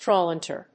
tráwl・nèt